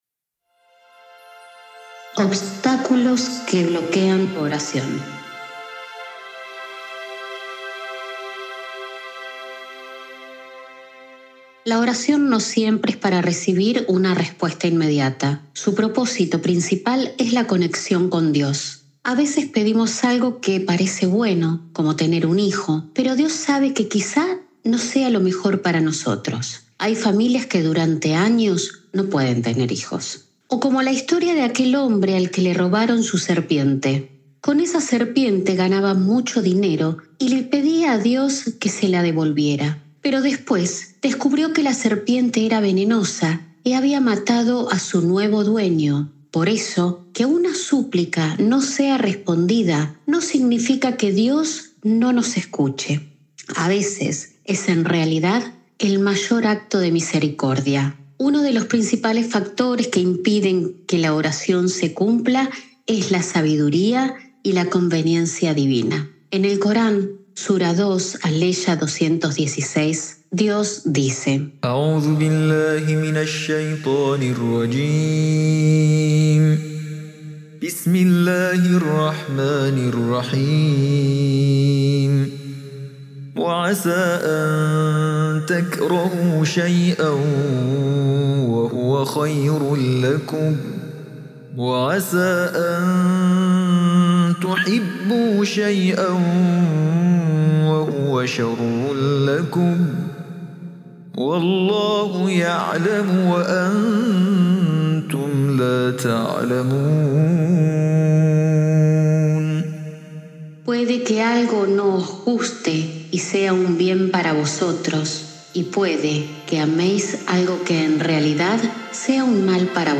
En este episodio de Voces de la Fe reflexionamos sobre los motivos por los que, a veces, nuestras oraciones no son respondidas. A través del Corán y las enseñanzas de los Imames, descubrimos que los mayores obstáculos están en el corazón, en las malas intenciones y en la falta de acción. 🎙 Locutora